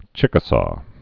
(chĭkə-sô)